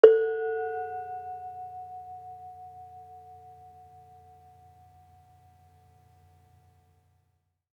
Bonang-A3-f.wav